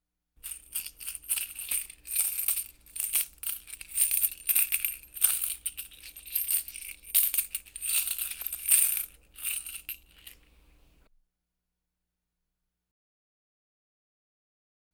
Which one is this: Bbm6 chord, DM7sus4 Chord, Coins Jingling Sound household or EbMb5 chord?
Coins Jingling Sound household